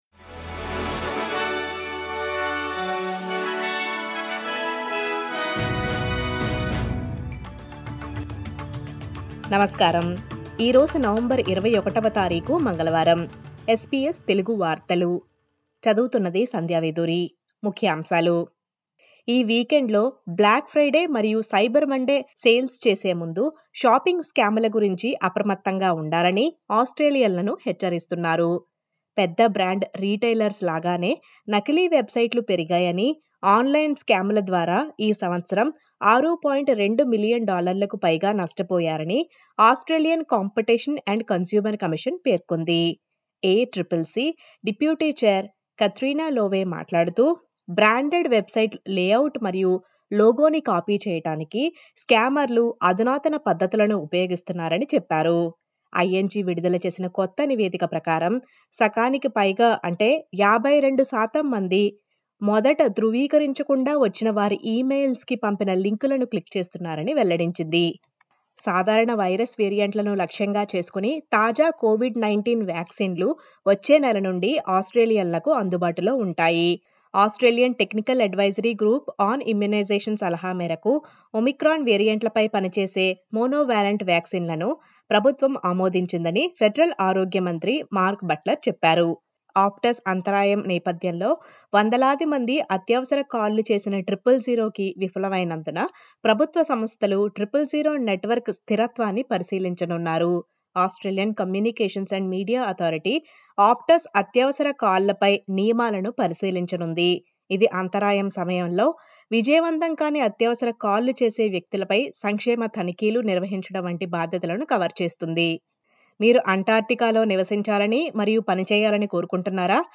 SBS తెలుగు వార్తలు.